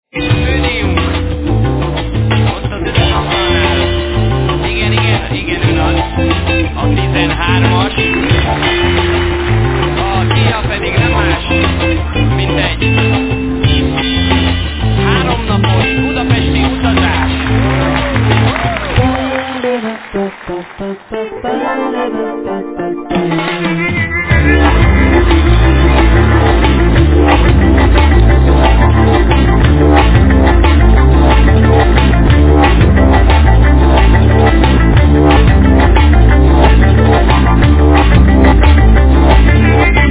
Vocals, Guitar
Guitar, Guitar synth
Drumas
Bass
Synthesizer, Guitar, Jew's harp, Violin, Percussions
Piano
Vocals, Mouth organ
Synthesizer, Machine-organ, Sitar, Violin, Percussions